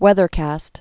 (wĕthər-kăst)